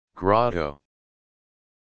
Click here to hear the pronunciation of grotto.